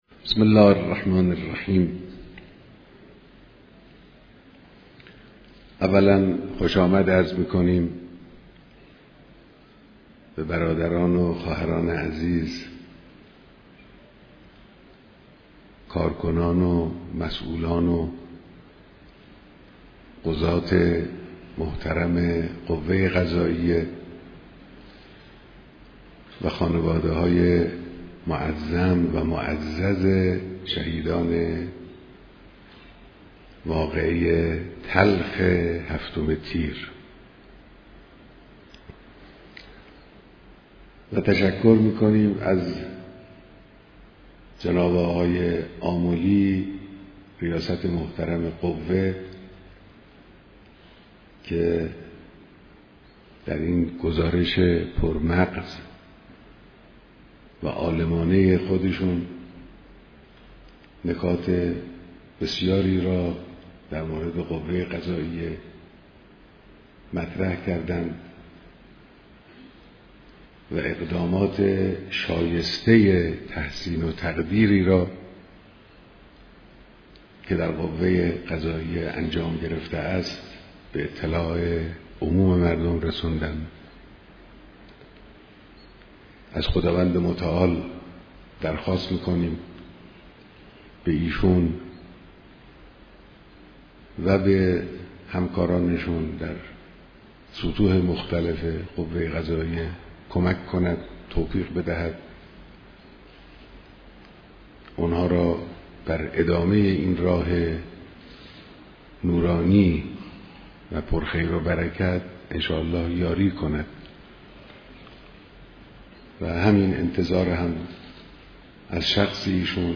بیانات در دیدار رئیس و مسئولان قوه قضائیه